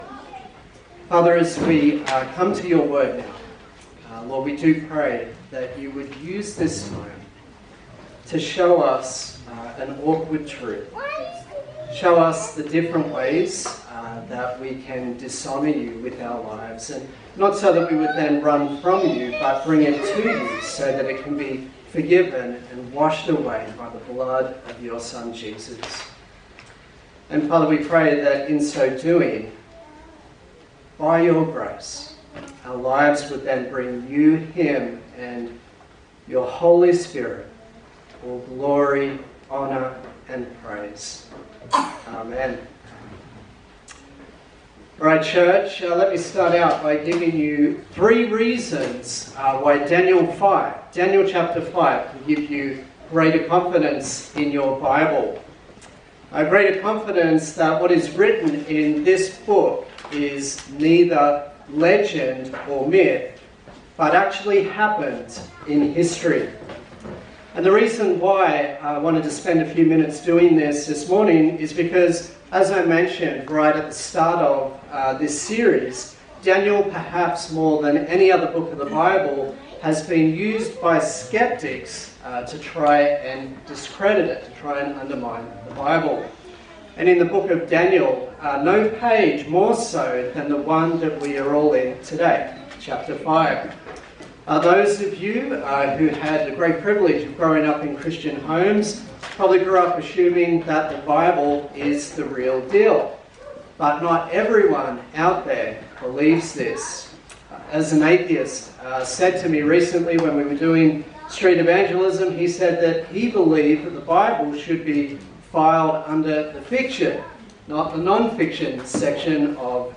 A sermon in the series on the book of Daniel
Service Type: Sunday Service